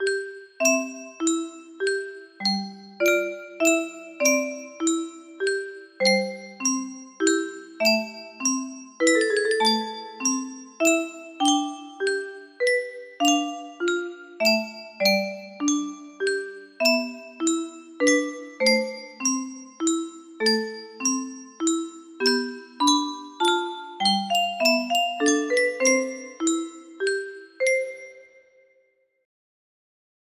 Chopin - Nocturnes In Eb Major, Op. 9, No. 2 music box melody
A lovely music box cover of Chopin's famous "Nocturnes", as played by the Epson SVM7910CE Melody IC.